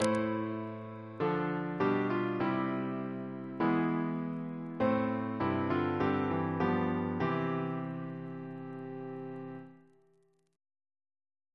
Single chant in C Composer: F. A. Gore Ouseley (1825-1889) Reference psalters: ACB: 233